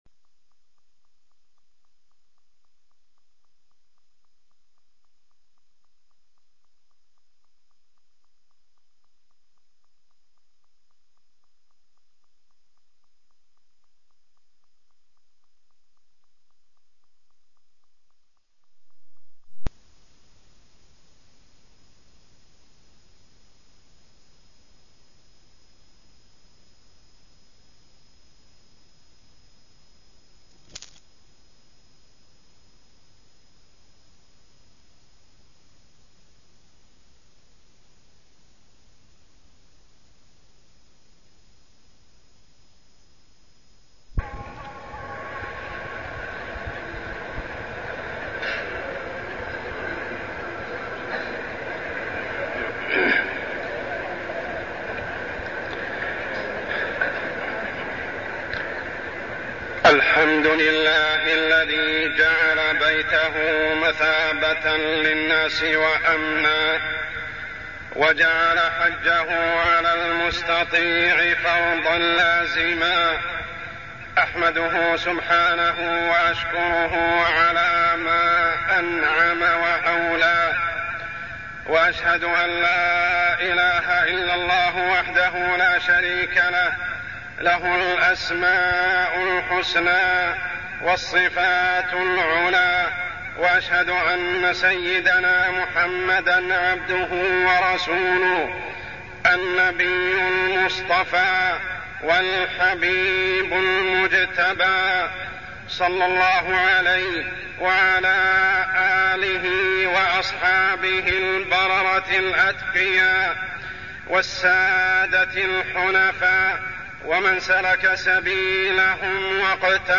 تاريخ النشر ٢ ذو الحجة ١٤١٩ هـ المكان: المسجد الحرام الشيخ: عمر السبيل عمر السبيل وفود حجاج بيت الله